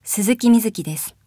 ボイスサンプル、その他